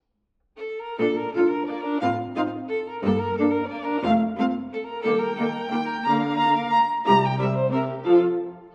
↑古い録音のため聴きづらいかもしれません！（以下同様）
伝統的なメヌエットです。
トリオが面白くて、ハンガリー風の民族性があると言われています。